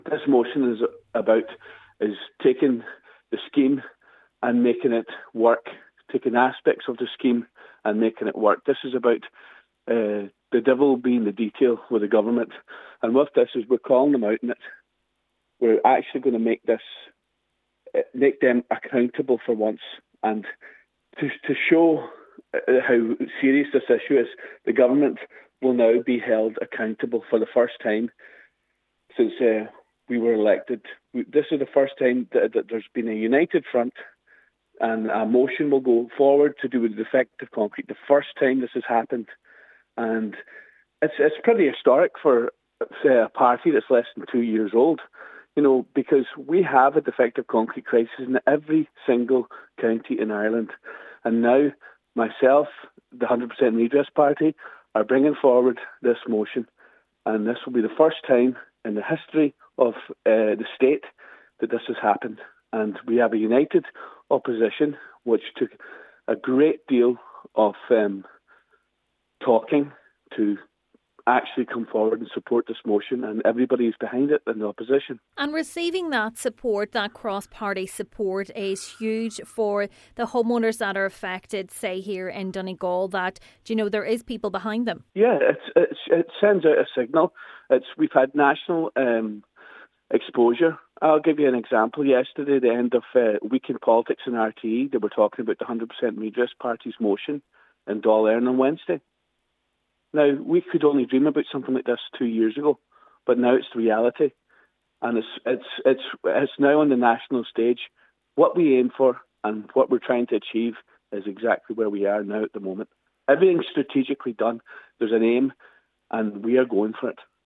Deputy Ward says it’s about time the Government is finally held to account: